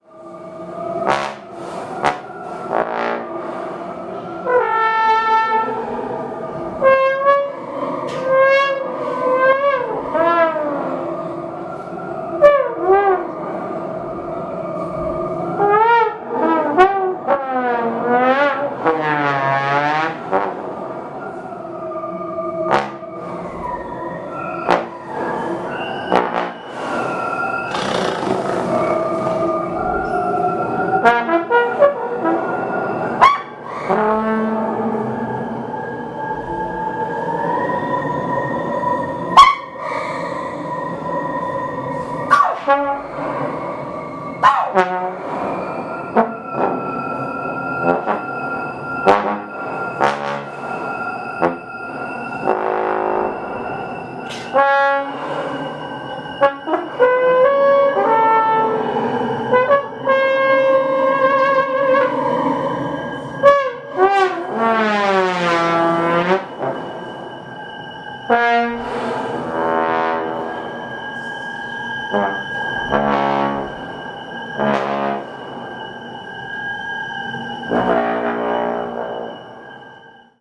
Audio recording done at Seattle site